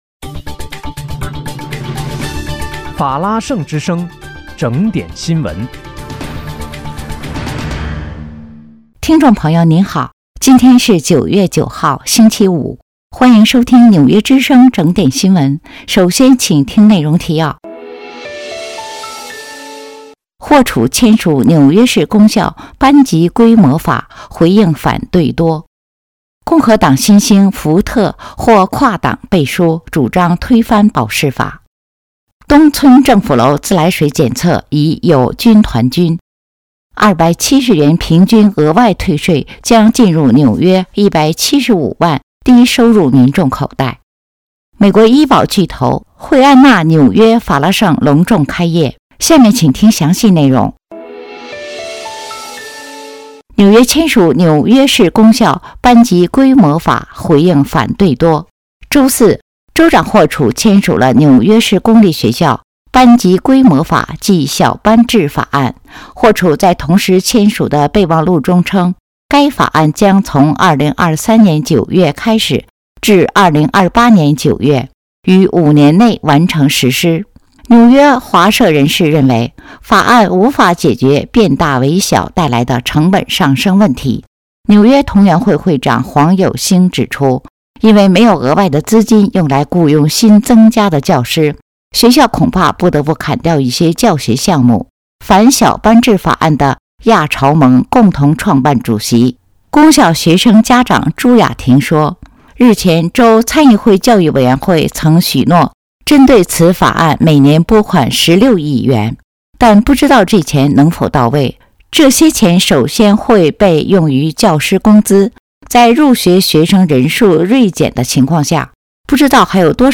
9月9日（星期五）纽约整点新闻